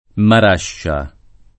[ mar # šša ]